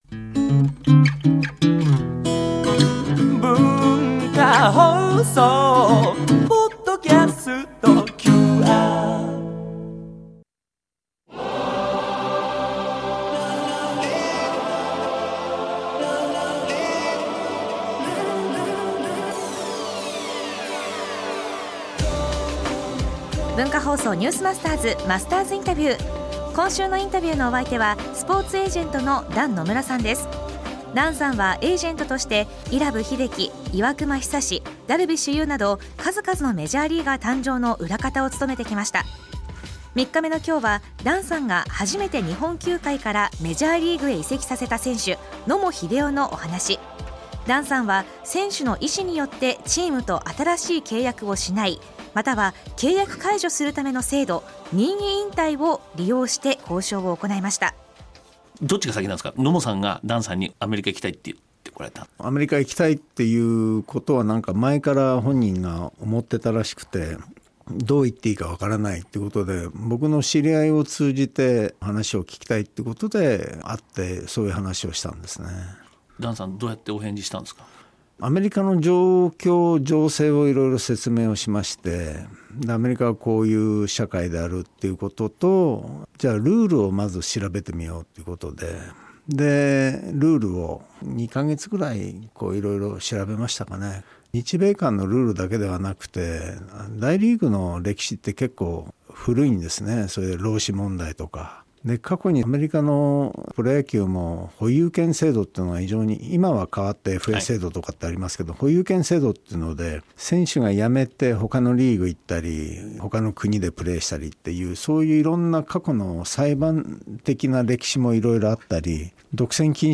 毎週、現代の日本を牽引するビジネスリーダーの方々から次世代につながる様々なエピソードを伺っているマスターズインタビュー。
今週のインタビューのお相手はKDNスポーツジャパン代表取締役でスポーツエージェントの団野村さん。
（月）～（金）AM7：00～9：00　文化放送にて生放送！